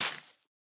Minecraft Version Minecraft Version snapshot Latest Release | Latest Snapshot snapshot / assets / minecraft / sounds / block / spore_blossom / break1.ogg Compare With Compare With Latest Release | Latest Snapshot
break1.ogg